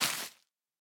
Minecraft Version Minecraft Version 1.21.5 Latest Release | Latest Snapshot 1.21.5 / assets / minecraft / sounds / block / sponge / break2.ogg Compare With Compare With Latest Release | Latest Snapshot